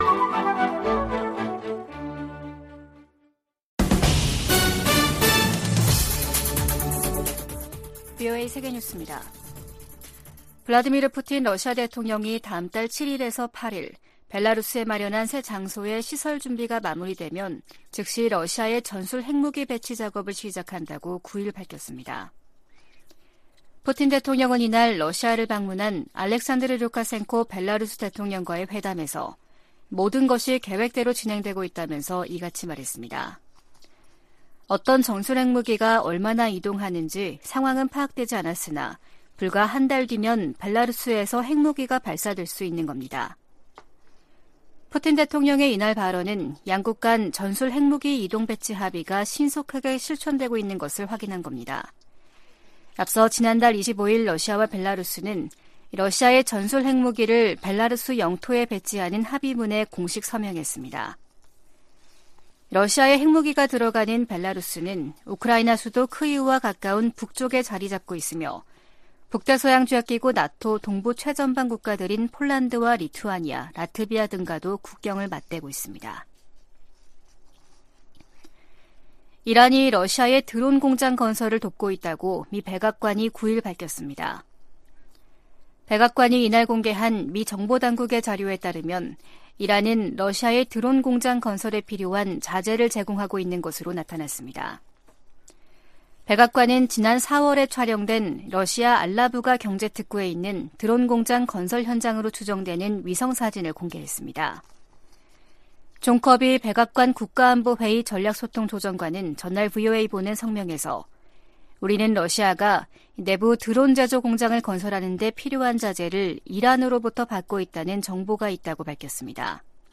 VOA 한국어 아침 뉴스 프로그램 '워싱턴 뉴스 광장' 2023년 6월 10일 방송입니다. 미 국무부가 한국의 새 국가안보전략을 환영한다고 밝혔습니다. 중국과 북한의 핵무력 증강에 대응해 미국도 핵전력을 현대화하고 있다고 국방부 고위 관리가 밝혔습니다. 북한이 군사정찰위성 발사 실패 이후 국제사회 비판 여론에 예민하게 반응하면서 주민들에겐 알리지 않고 있습니다.